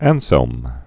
(ănsĕlm), Saint 1033-1109.